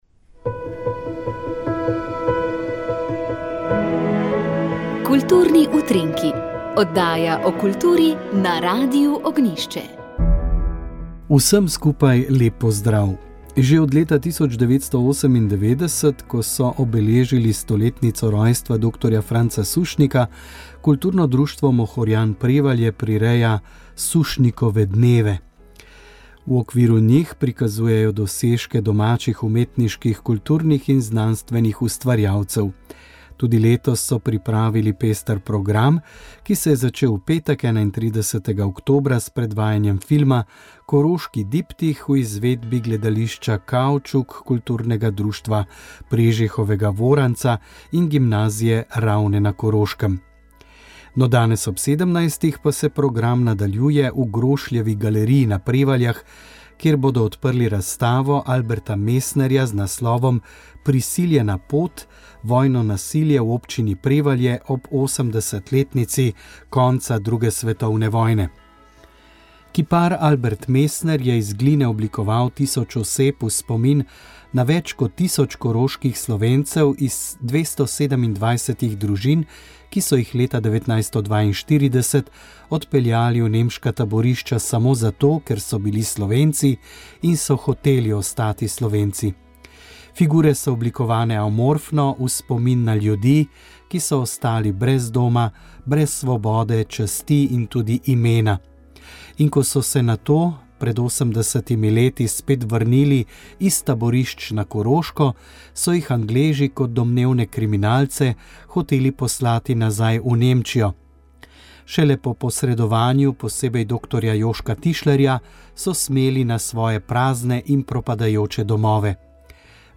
sodobna krščanska glasba